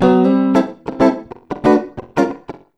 92FUNKY  7.wav